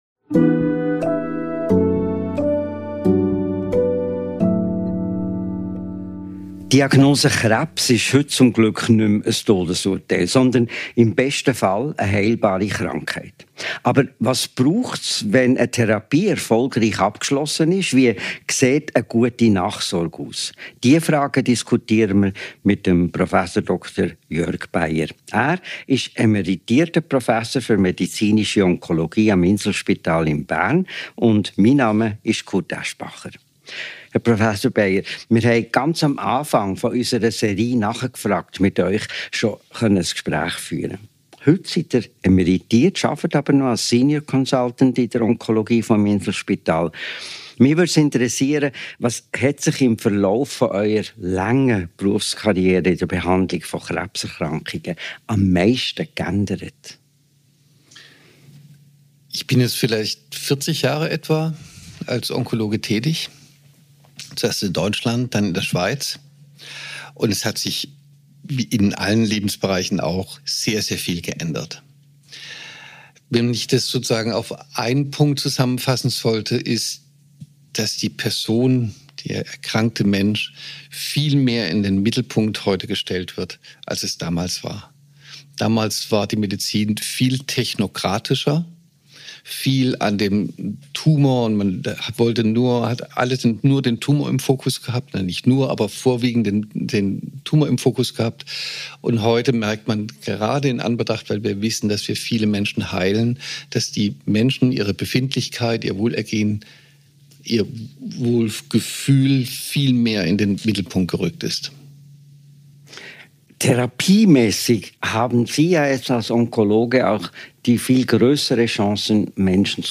Ein Gespräch über unsichtbare Narben, die Rückkehr in einen neuen Alltag und das Leben nach dem Krebs.